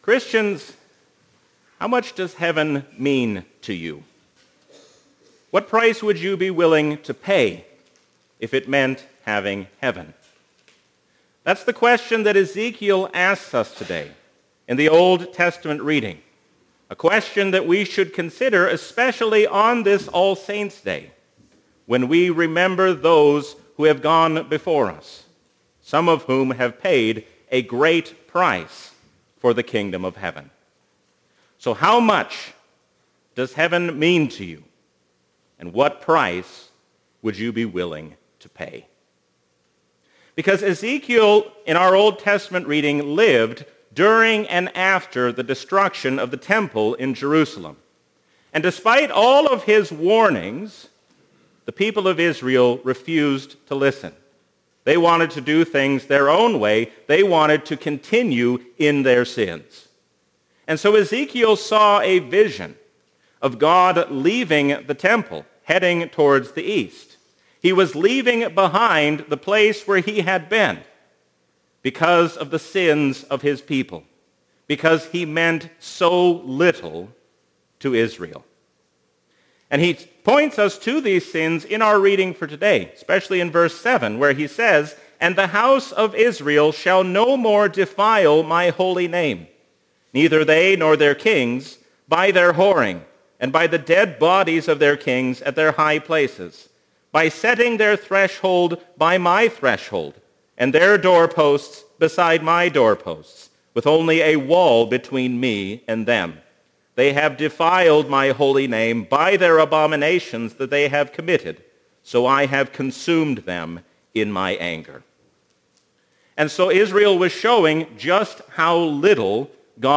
Sermons – St. Peter and Zion Lutheran